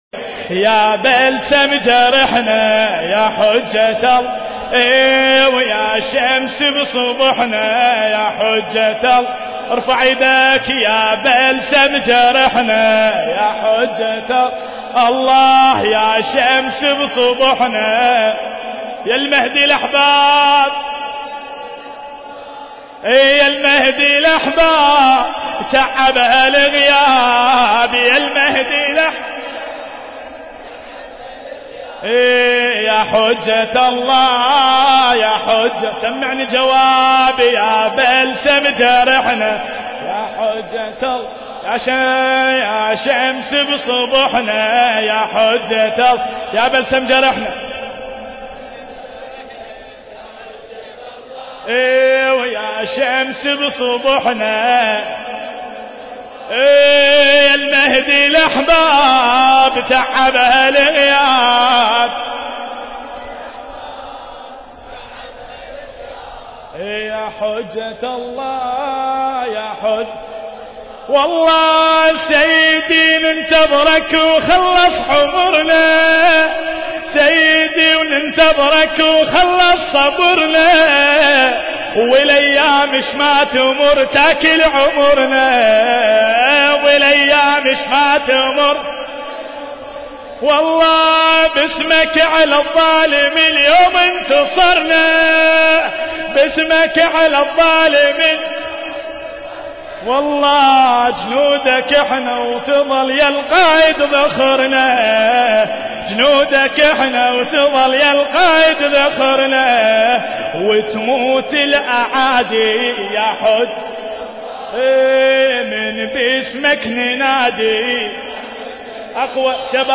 المكان: العتبة العلوية المقدسة